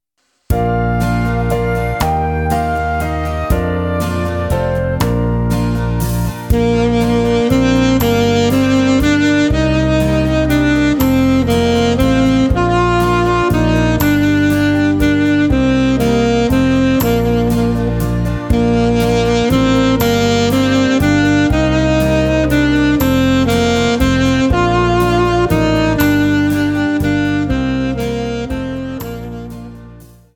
Saxophone Alto